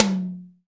Index of /90_sSampleCDs/Roland - Rhythm Section/TOM_Real Toms 1/TOM_Dry Toms 1
TOM ATTAK 03.wav